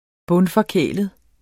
Udtale [ ˈbɔnfʌˈkεˀləð ]